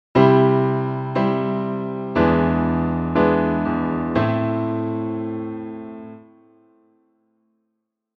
In the second progression, however, the E7 to Am feels like a great build up and release of tension. Not only does the Am feel like a welcome release from the tension of the unexpected E, it also feels like it could function as our new home chord if we continued to stay on it.
2_C-E7-Am.mp3